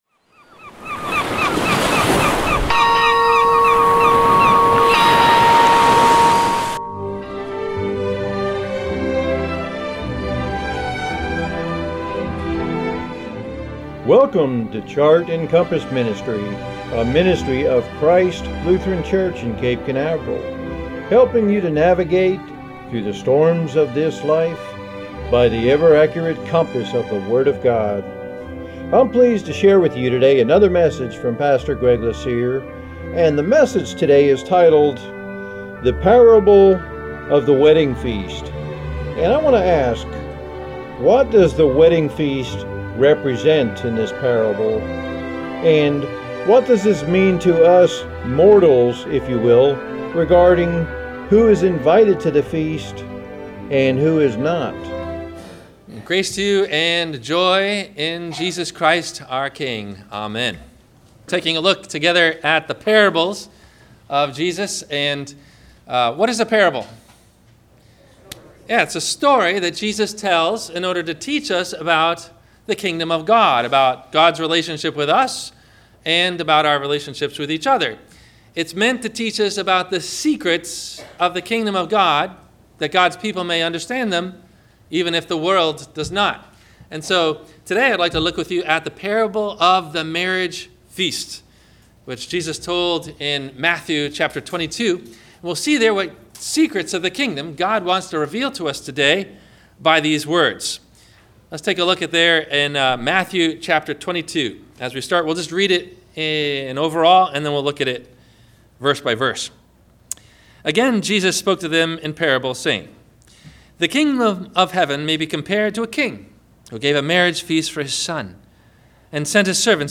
The Parable of The Wedding Feast – WMIE Radio Sermon – December 19 2016 - Christ Lutheran Cape Canaveral